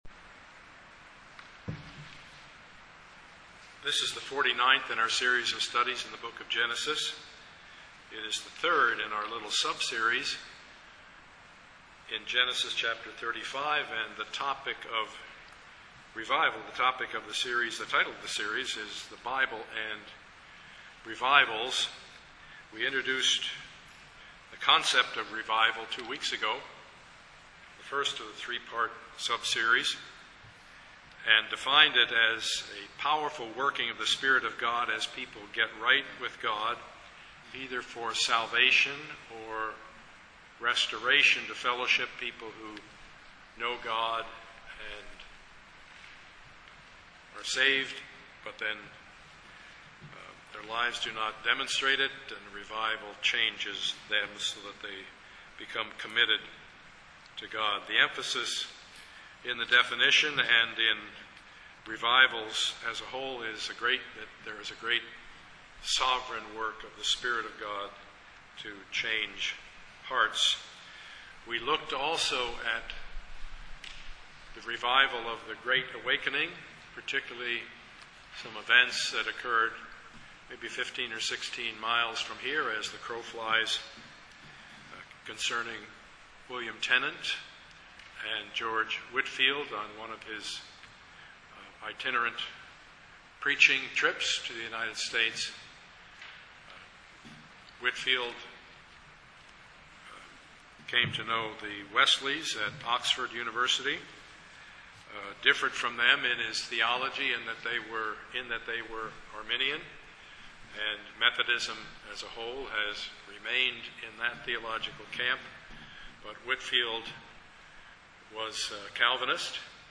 Service Type: Sunday morning
Part 49 of the Sermon Series Topics: Jacob , Revival